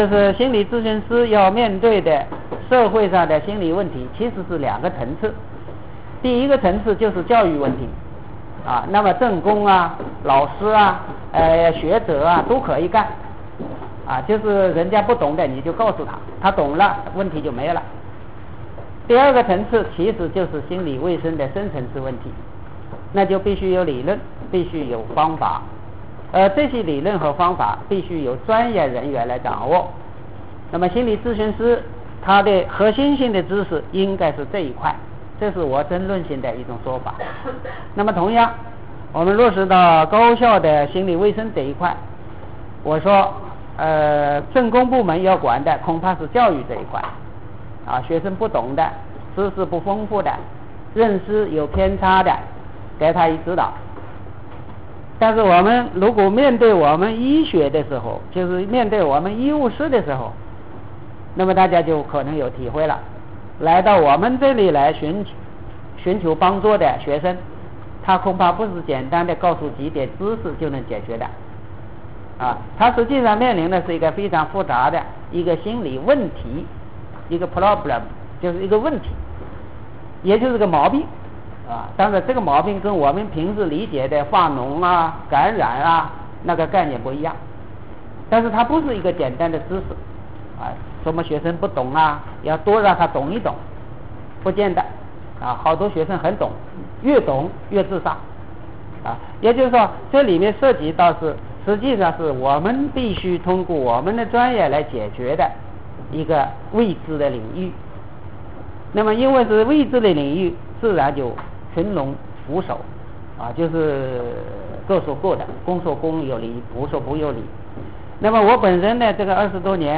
心理应激理论与心理咨询-浙江省高校医院培训班录音(2003年)心理应激理论解读(2.5小时，35.5M)
人本理论与心理咨询-咨询员培训录音(2003)人本理论与心理咨询(1.5小时，21M) （注：以上录音内容是新发现的多年前用MP3随意录制的，并无计划，也不成熟，仅供有兴趣者参考）